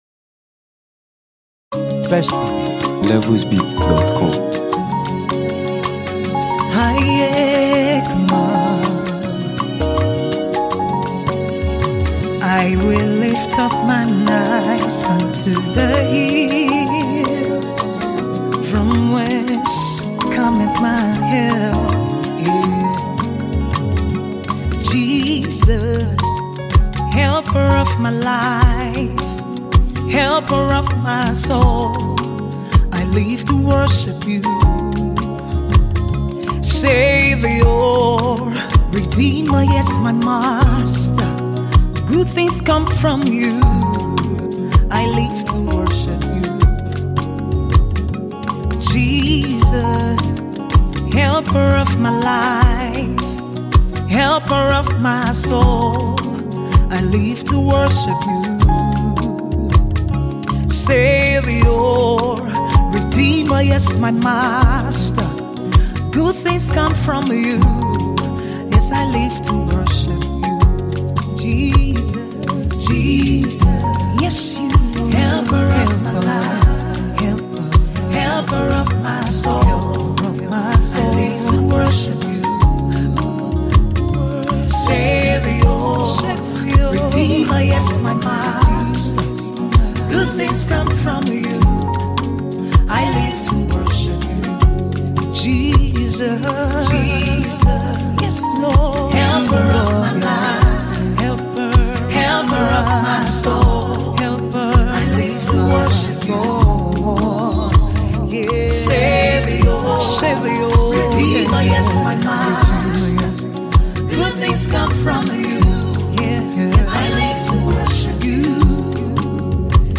gospel singer
soothing melodies, and an atmosphere of worship